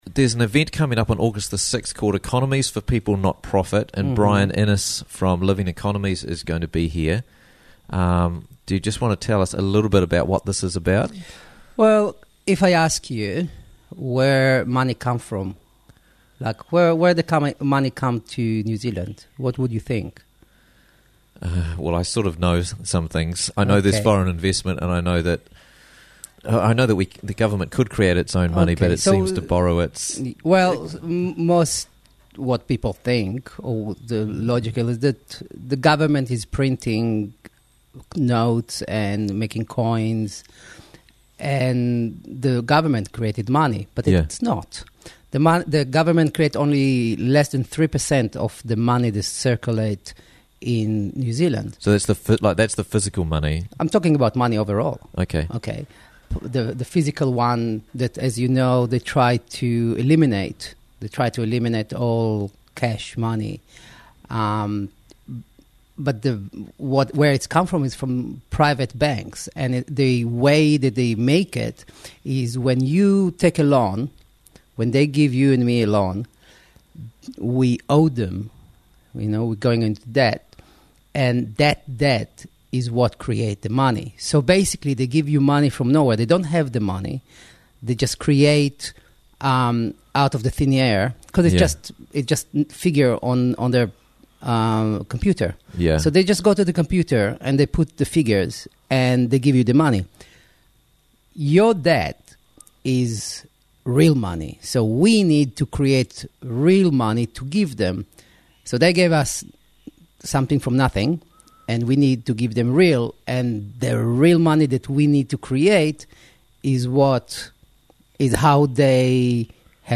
Economies for People - Workshop Coming to Raglan - Interviews from the Raglan Morning Show